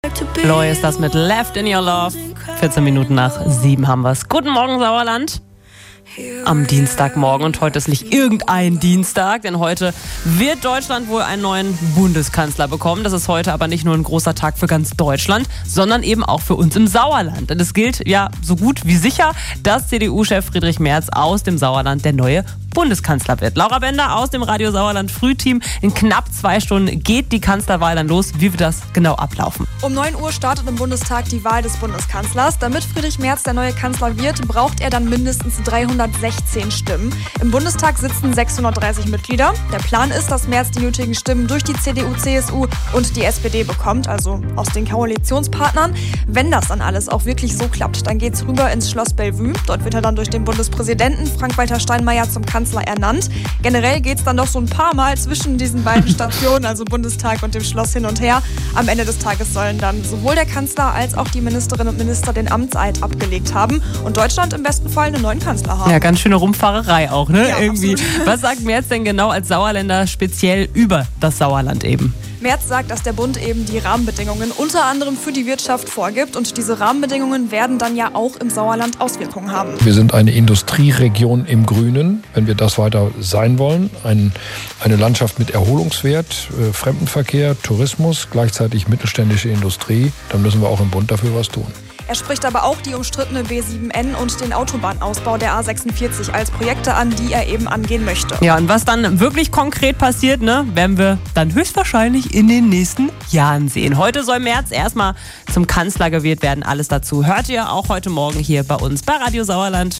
mitschnitt-merz-kanzler.mp3